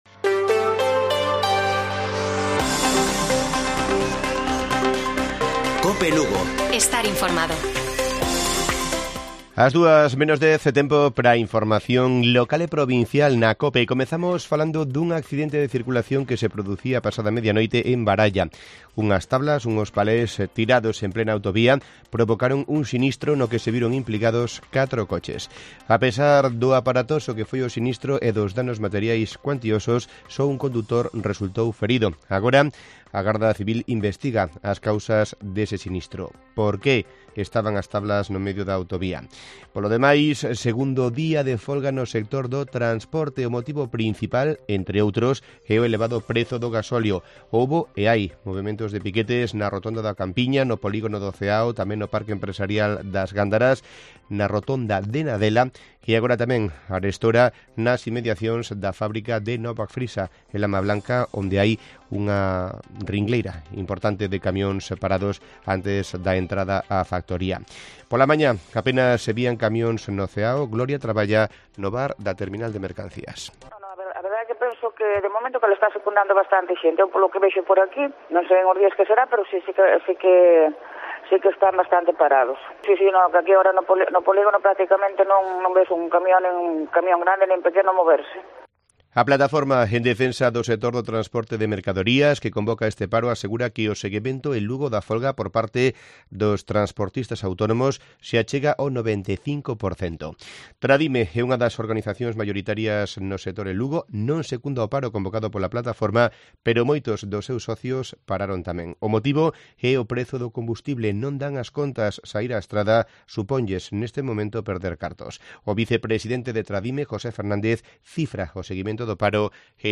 Informativo Mediodía de Cope Lugo. 15 de marzo. 14:20 horas